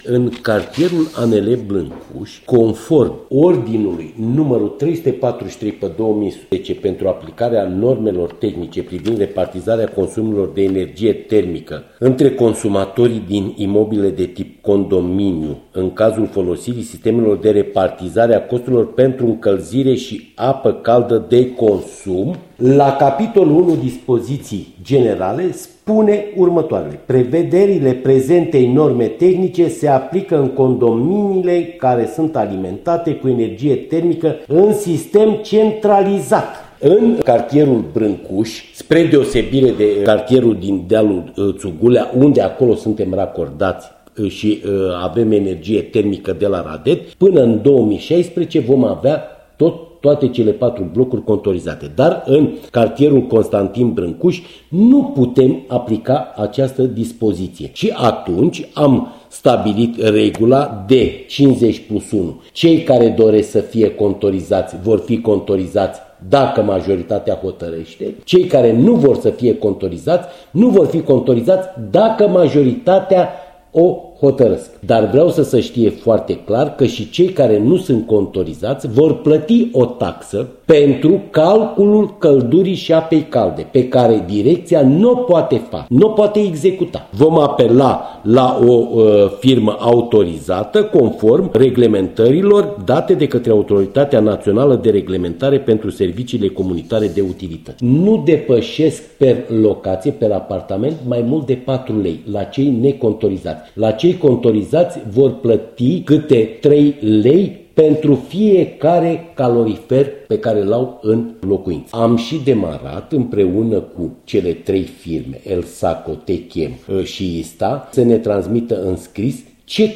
Un interviu
interviu-repartitoare.mp3